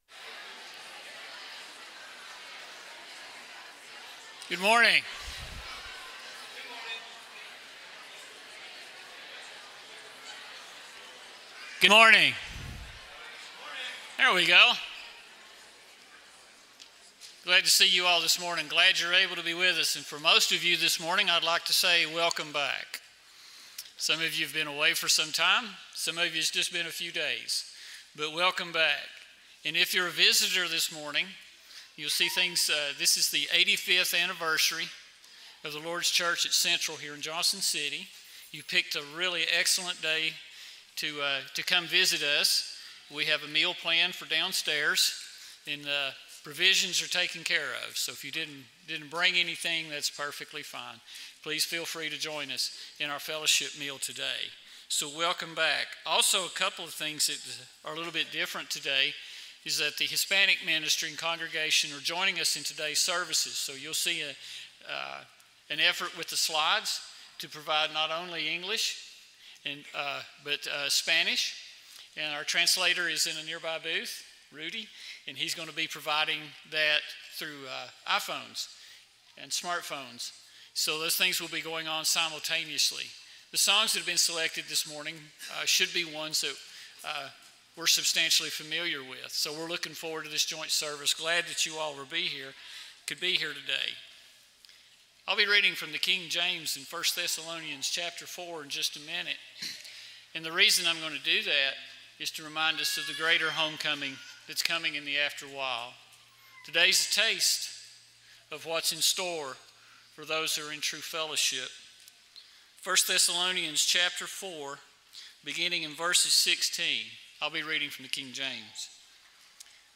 (Hebrews 13:14, English Standard Version Series: Sunday AM Service